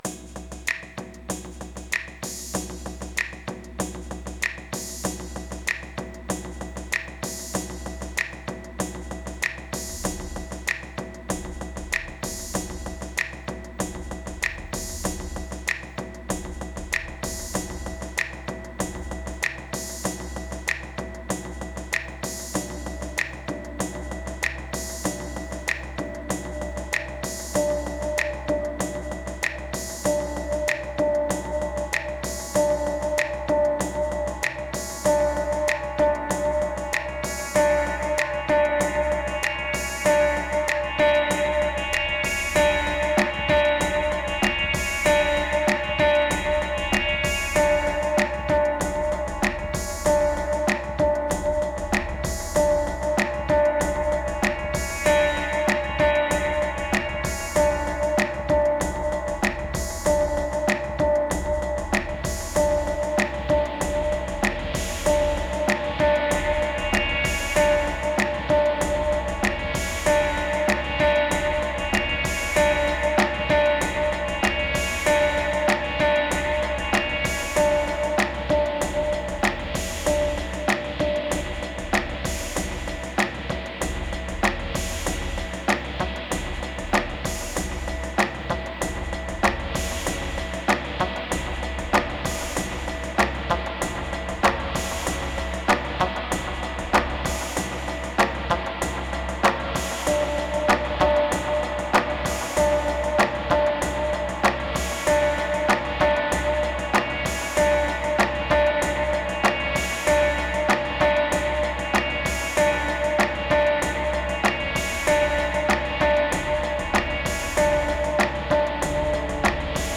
Warmer than expected.
The first take got so dark that I had to upbeat the track.